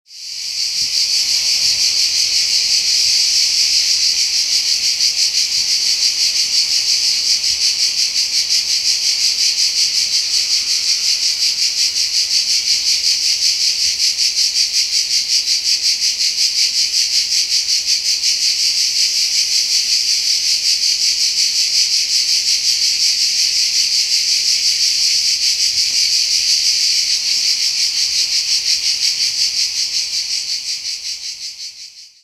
Chant de cigales
Chant de Cigales enregistré dans la garrigue du sud de la France
musicextrait_chantdecigales.mp3